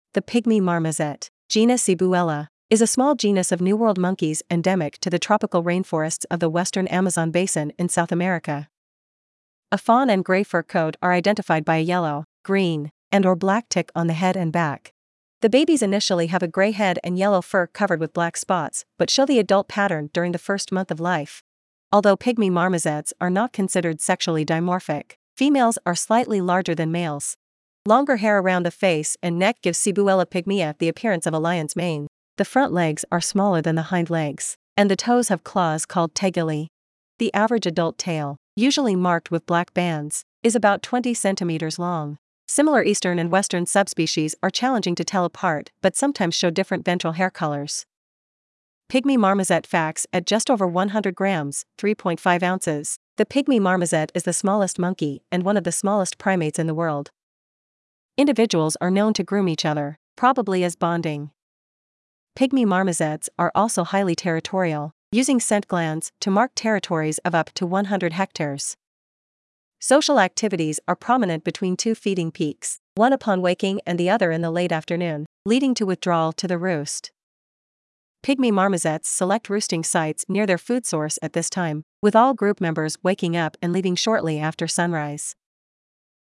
Pygmy Marmoset
Their ‘babbling’ follows patterns similar to human baby speech[4].
Pygmy-Marmoset.mp3